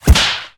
slapstickBigPunch.ogg